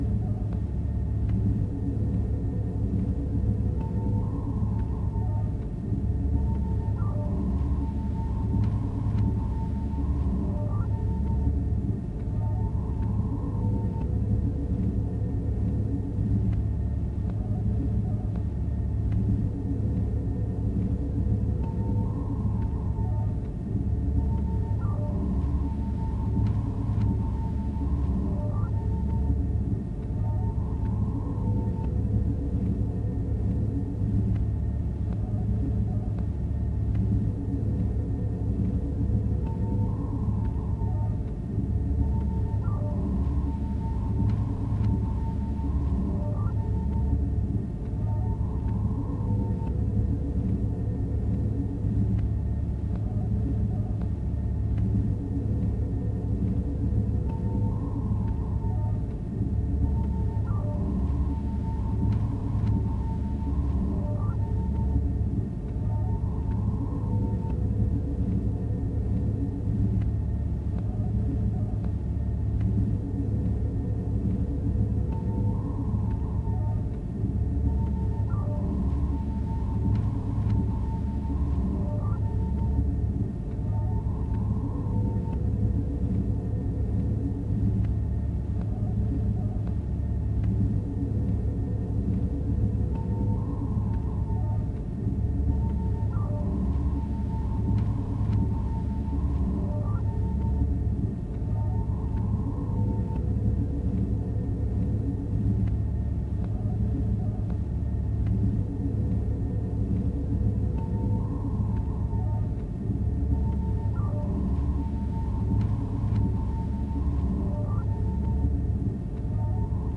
observationroom.wav